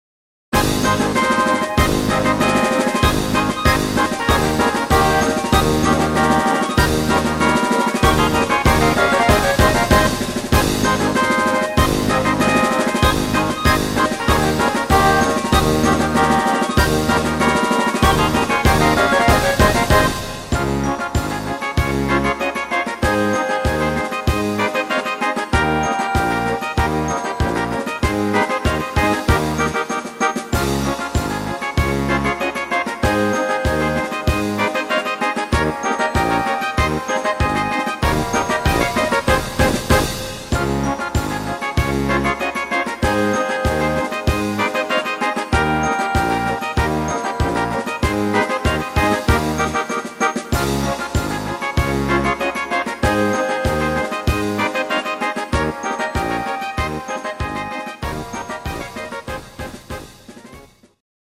instr. Orchester
Rhythmus  Marsch
Art  Blasmusik, Instrumental Orchester, Traditionell